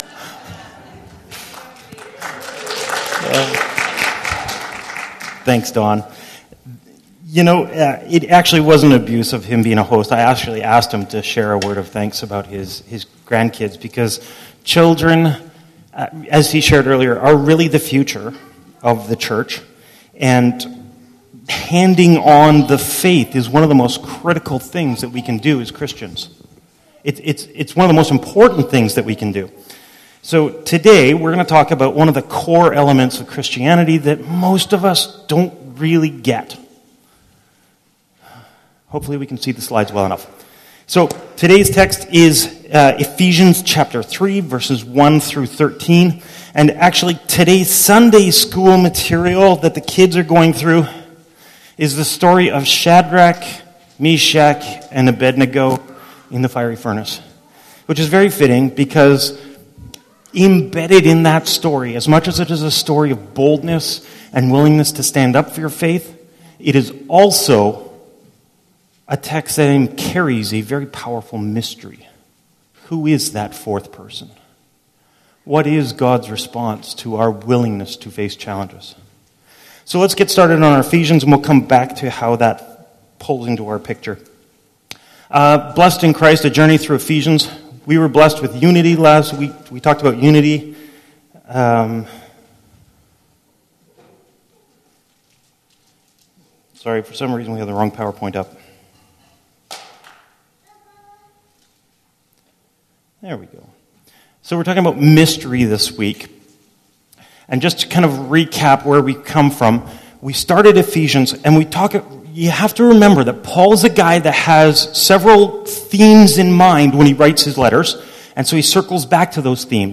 PG Sermons